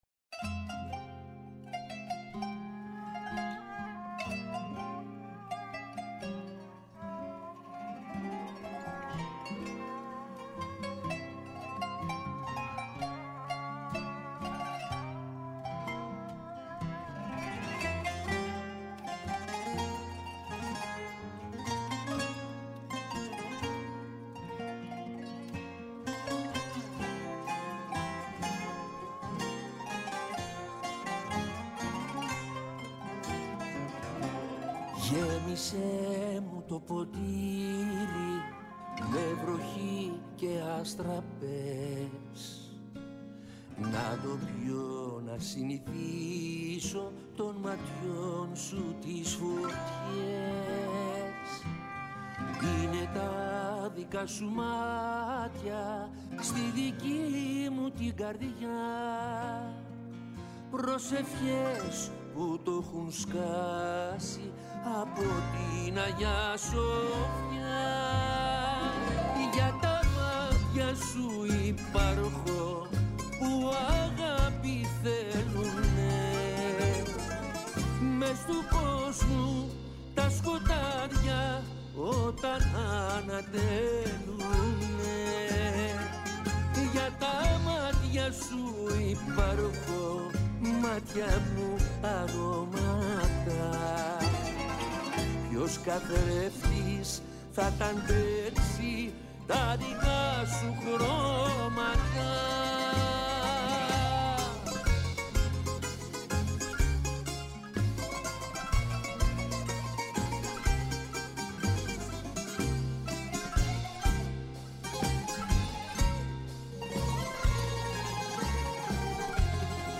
Καλεσμένος σήμερα στο στούντιο του Πρώτου Προγράμματος, ο Γιώργος Φλωρίδης, υπουργός Δικαιοσύνης.